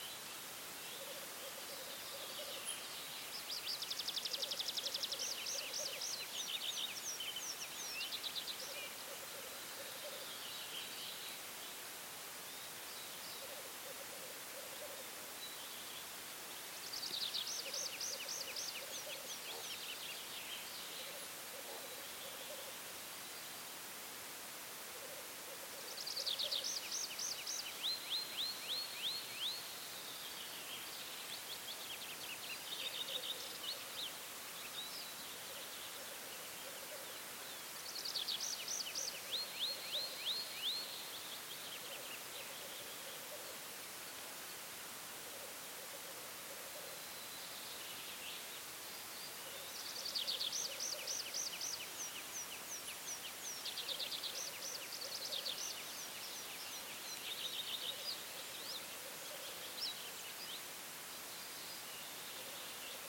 Aamu suolla
Teeret soivat kaukana suon toisella laidalla ja laulujoutsenpari käväisi pikavisiitillä suon keskellä.
Nauhoitin suon äänimaisemasta pari hetkeä. Ensimmäisessä äänitteessä parhaiten edustettuina ovat käki, peippo, laulujoutsen ja lopussa kurki. Aivan nauhan alussa laulaa kirjosieppo.